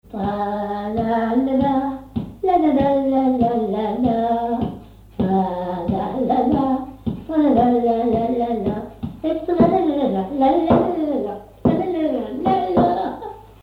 Base d'archives ethnographiques
Air de danse chantée
Localisation Cancale (Plus d'informations sur Wikipedia)
Usage d'après l'analyste gestuel : danse ;
Genre brève
Catégorie Pièce musicale inédite